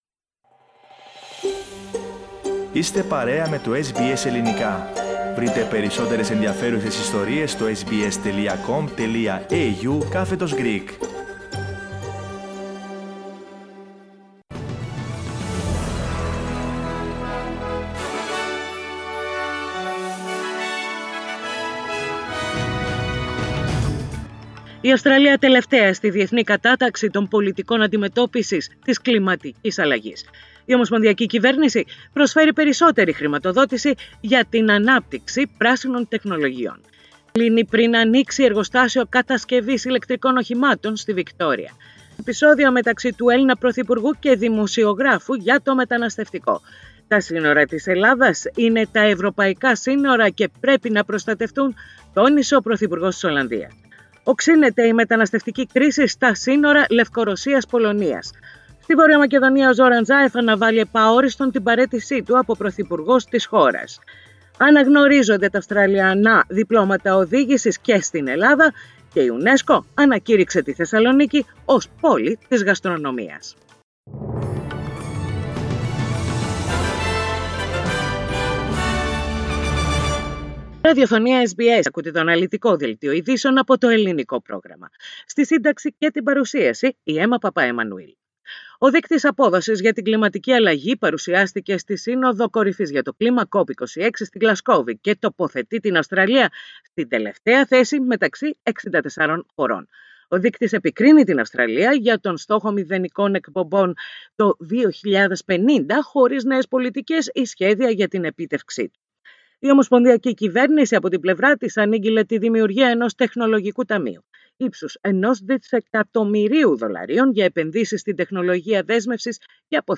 Ειδήσεις στα Ελληνικά - Τετάρτη 10.11.21